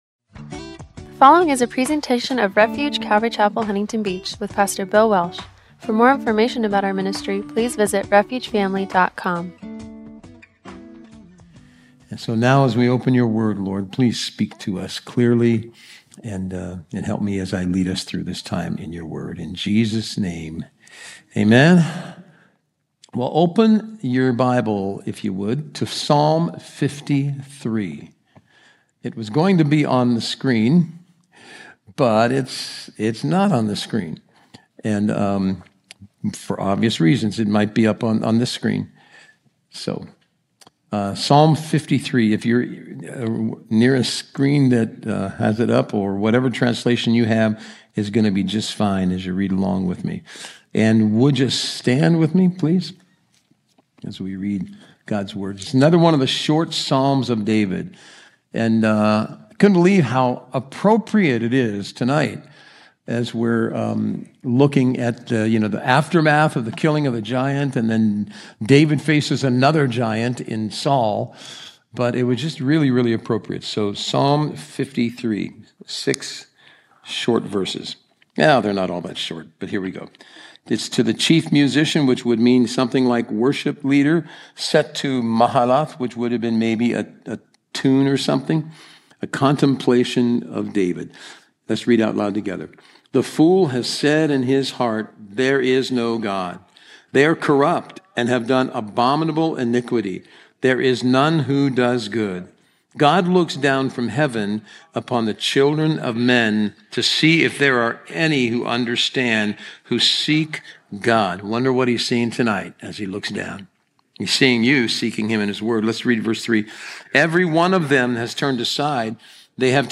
“David’s New Best Friend”-1 Samuel 18:1-4 – Audio-only Sermon Archive
Service Type: Wednesday Night